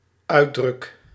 Ääntäminen
Ääntäminen NL: IPA: /ˈœy̯drʏk/ IPA: /ˈʌy̯drʏk/ BE: IPA: /ˈœy̯drʏk/ IPA: /ˈœːdrʏk/ Haettu sana löytyi näillä lähdekielillä: hollanti Käännöksiä ei löytynyt valitulle kohdekielelle.